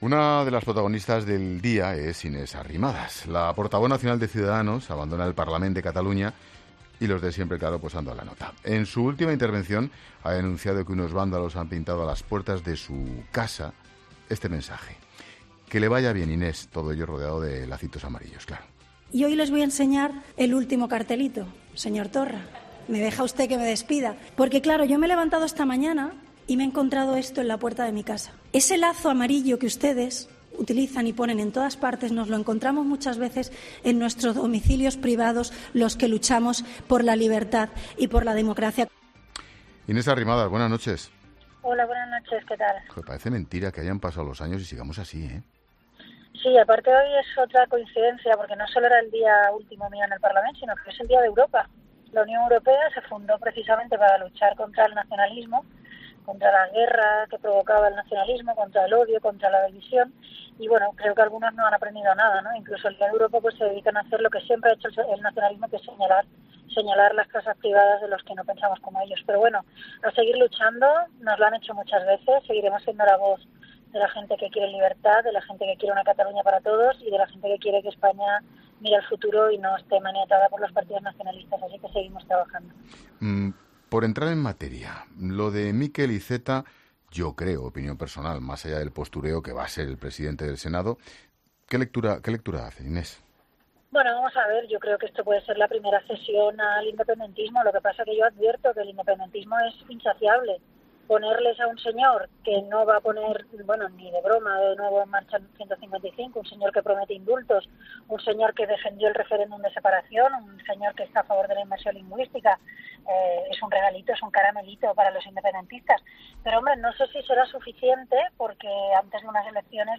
La hasta ahora líder de la formación naranja en la comunidad autónoma ha pasado por los micrófonos de La Linterna de COPE , donde ha analizado la elección de Miquel Iceta por el PSOE como más que posible presidente del Senado .